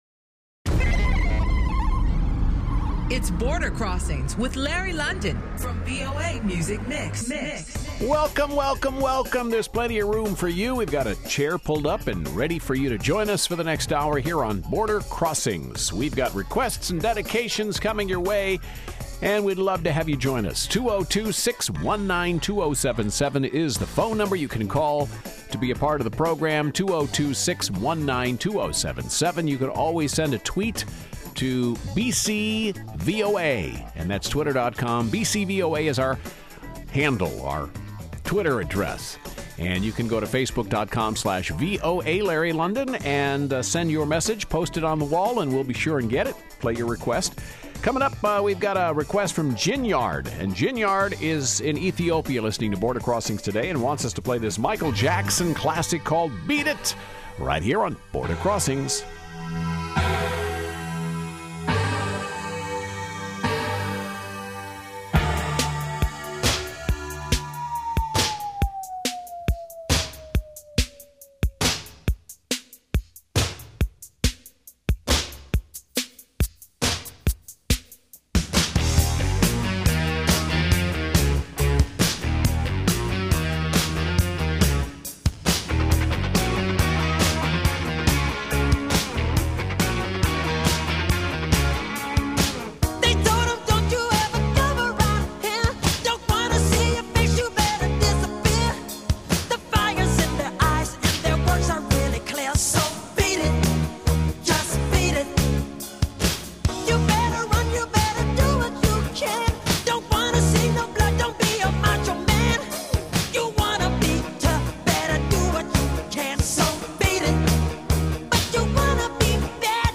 live
international music request show